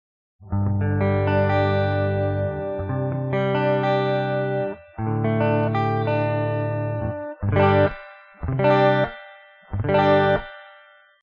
cleanreverbtube.mp3